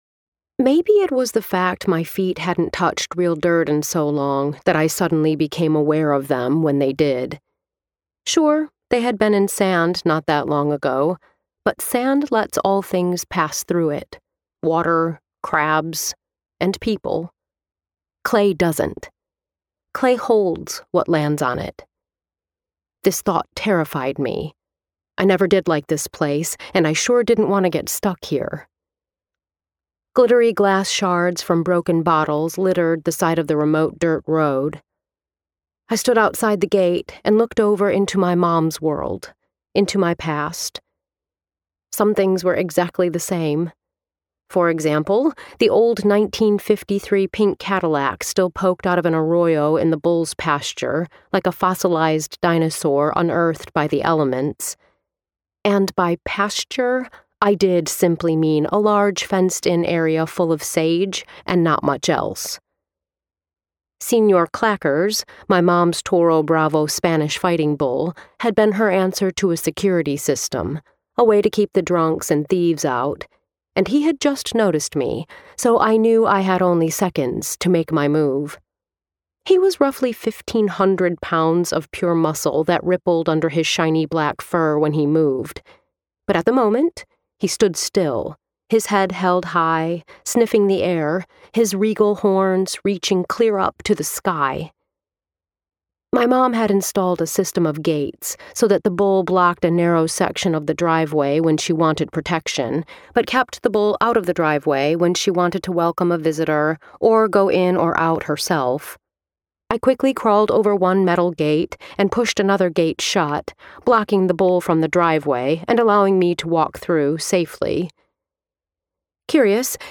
Road to Enchantment - Vibrance Press Audiobooks - Vibrance Press Audiobooks